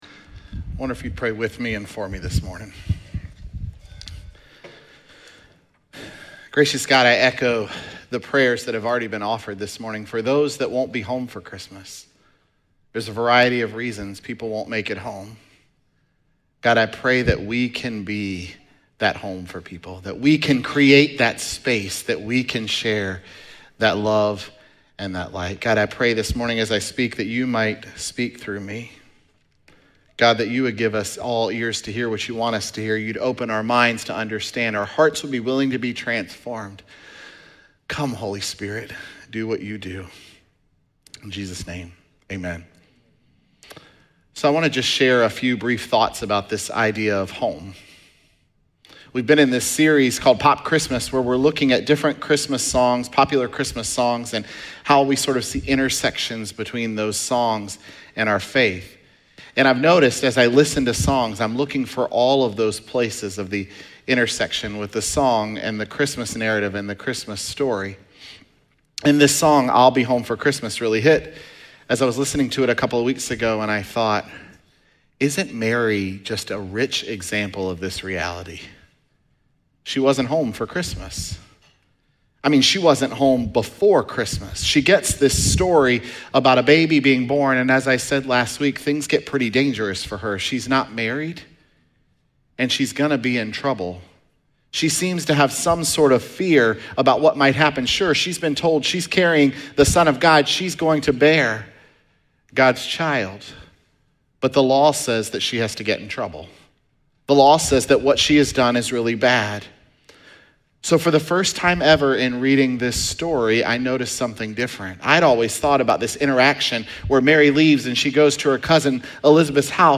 Sermons
Dec15SermonPodcast.mp3